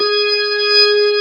55o-org15-G#4.aif